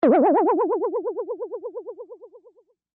Boing